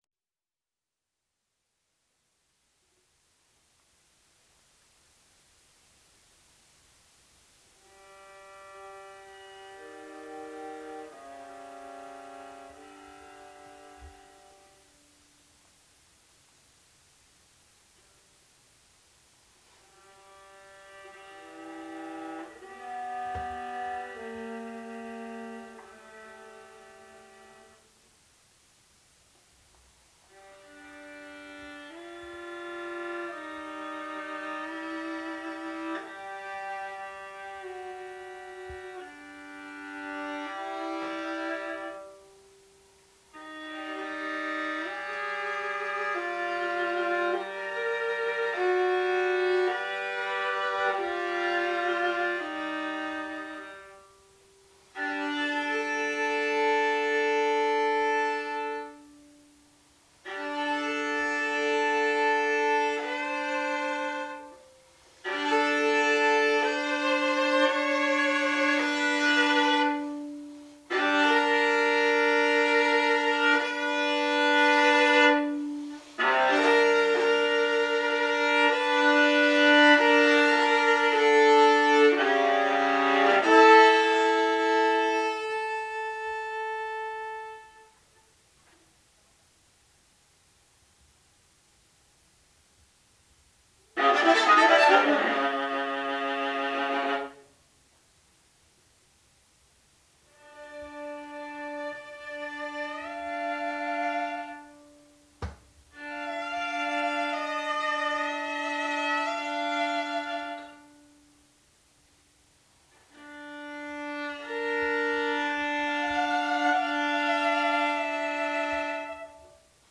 für Viola solo